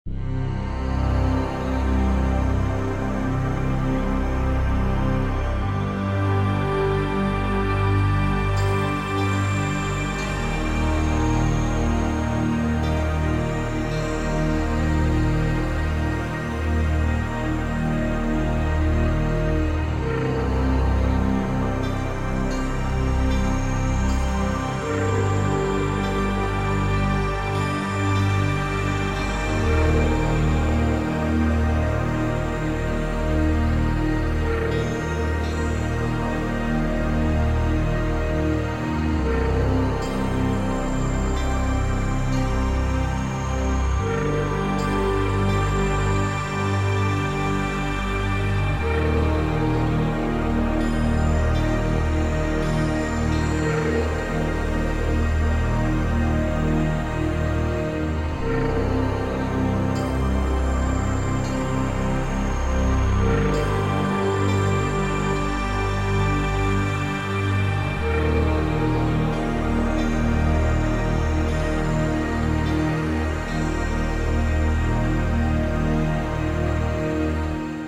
This ambient track has a mysterious feel.
Ambient
Mysterious